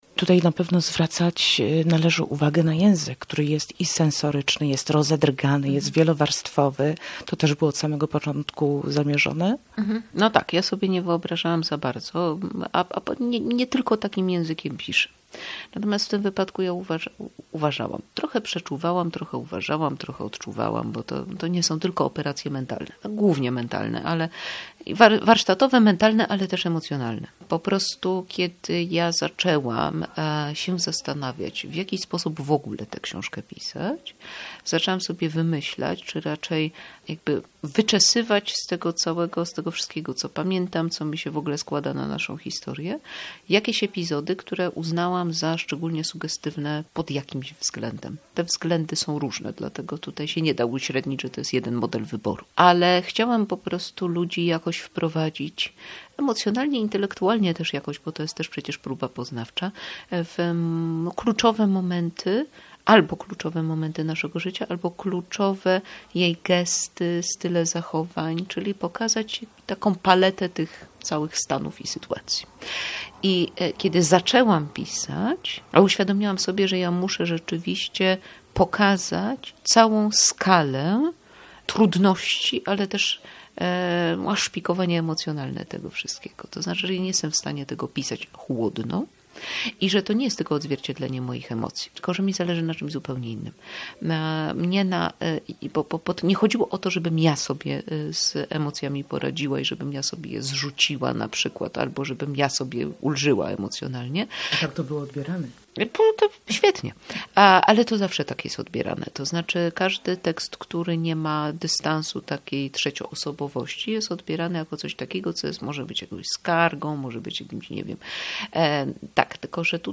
Dziś w Spisie Treści rozmowa z Elizą Kącką - laureatką Literackiej Nagrody Nike 2025 oraz Nagrody Czytelników za esej "Wczoraj byłaś zła na zielono". To książka głęboka, intymna o relacji matki z córką będącą w spektrum autyzmu.